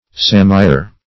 Search Result for " sammier" : The Collaborative International Dictionary of English v.0.48: Sammier \Sam"mi*er\, n. A machine for pressing the water from skins in tanning.